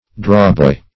Drawboy \Draw"boy`\, n. (Weaving)